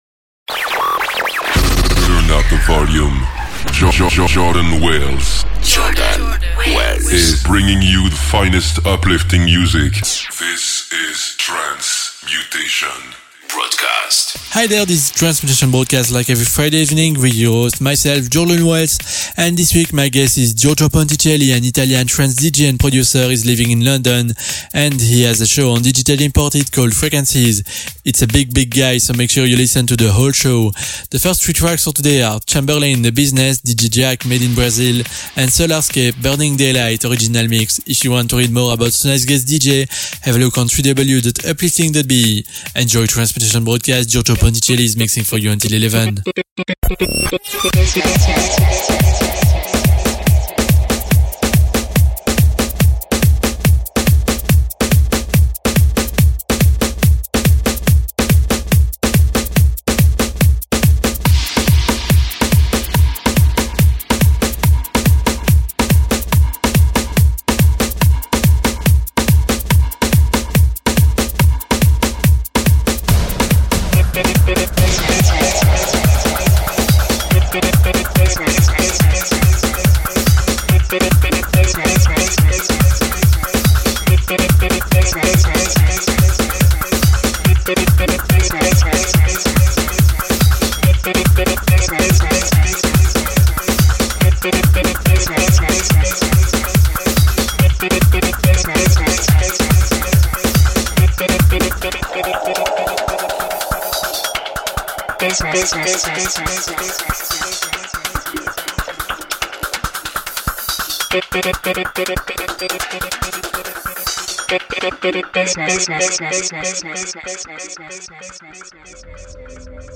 Trance/techtrance DJ & Producer from Italy.
uplifting